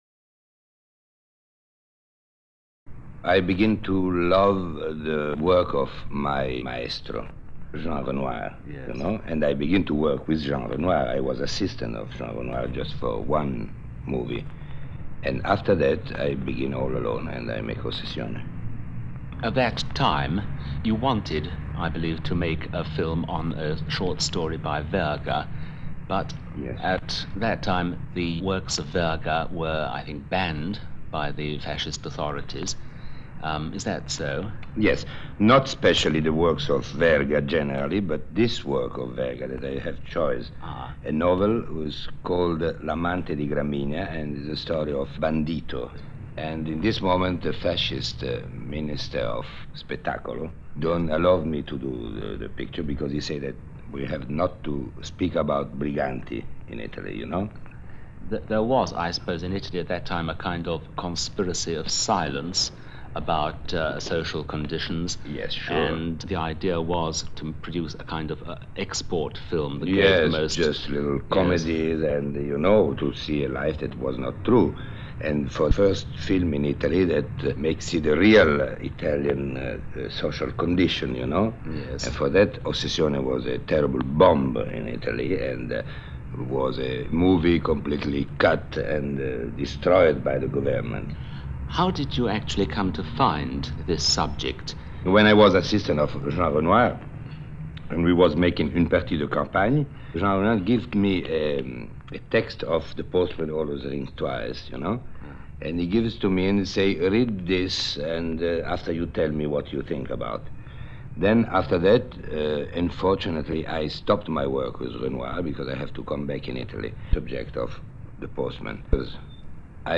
BBC-Luchino-Visconti-Interview-1963.mp3